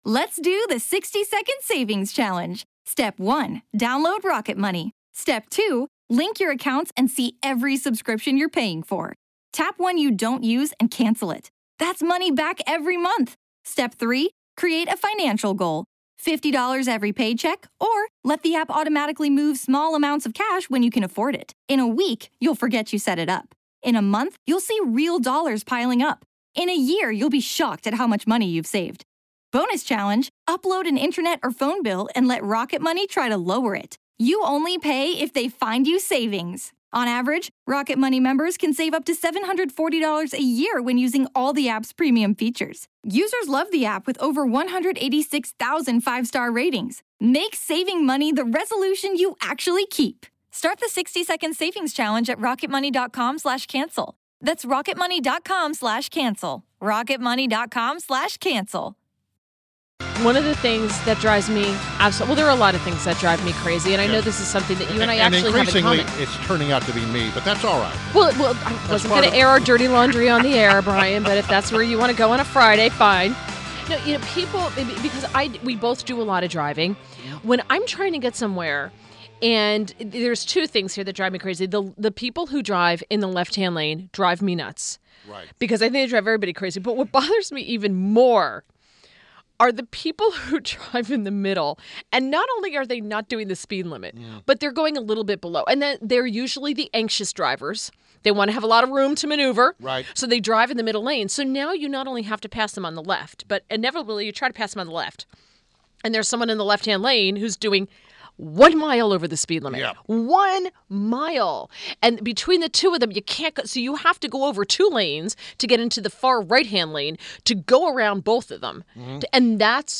WMAL Interview - WILLIAM FOLDEN - 03.31.17
INTERVIEW – Frederick County DELEGATE WILLIAM FOLDEN — a Republican member of the Maryland House of Delegates, representing District 3B.